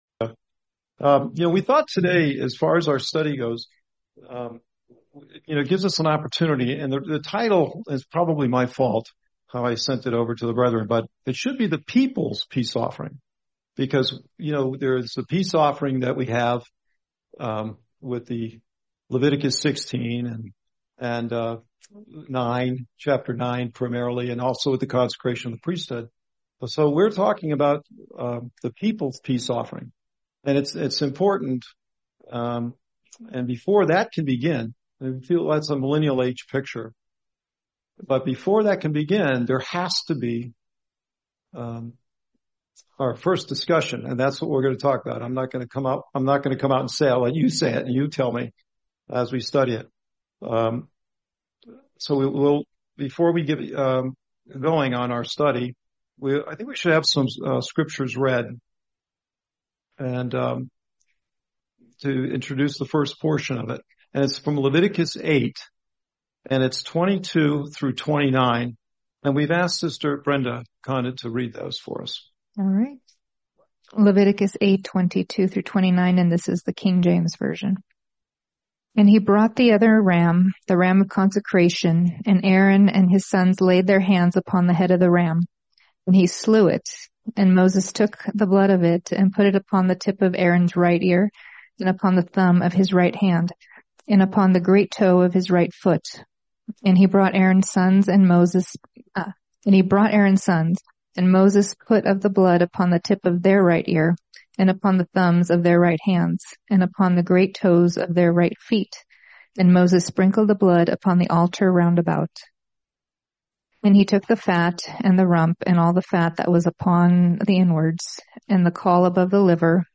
Series: 2026 Albuquerque Convention